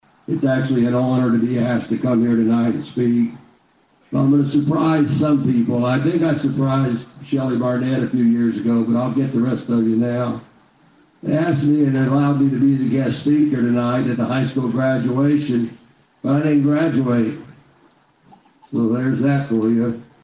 Copan High School Graduation 2025
The Copan High School graduation was broadcast live on 104.9 KRIG and was sponsored by Bartnet IP and OK Federal Credit Union.